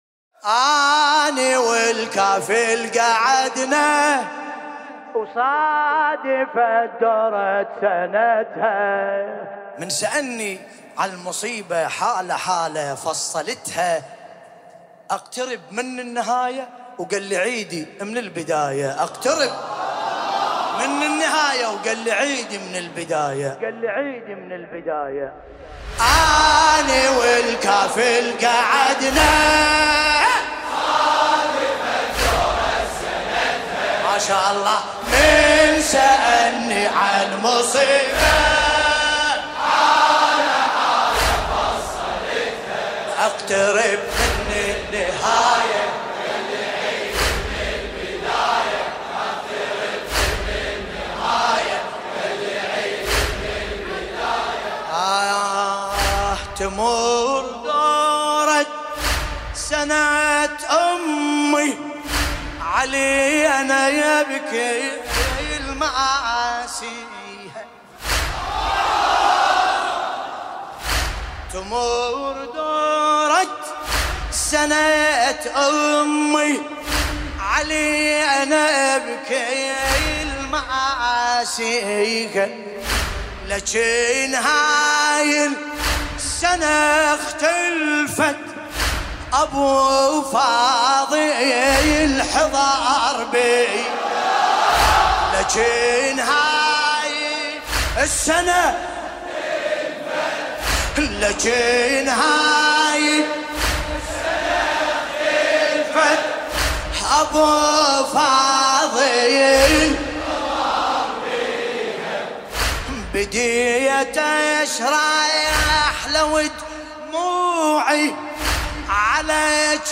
ملف صوتی آني والكافل بصوت باسم الكربلائي
الرادود : الحاج ملا باسم الكربلائيالمكان : هيئة جواد الأئمة ، العزيزية واسط المناسبة : الليالي الفاطمية ، ليلة 4 جمادى الأولى 1442